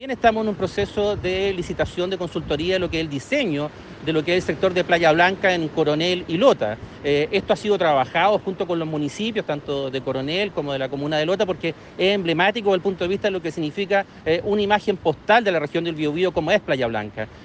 Por su parte, el seremi de Obras Públicas, Hugo Cautivo, afirmó que quieren realizar un proyecto similar en Playa Blanca.
cuna-colcura-playa-blanca.mp3